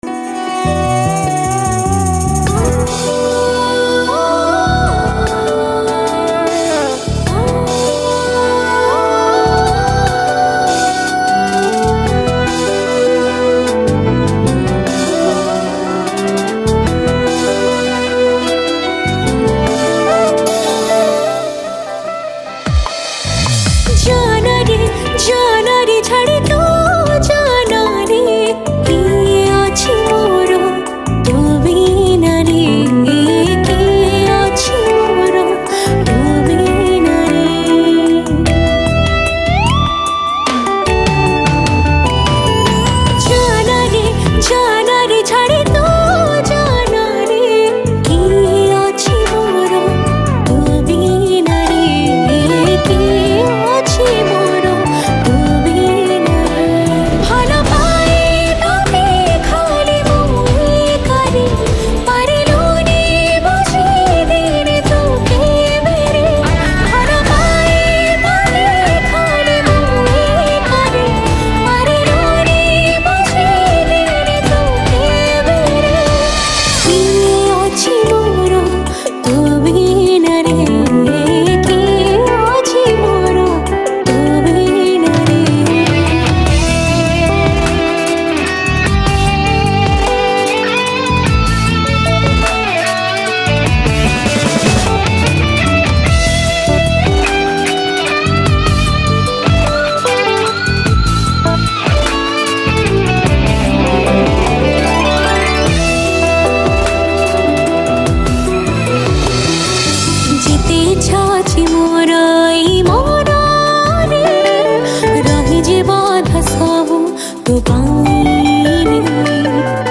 Soulful Song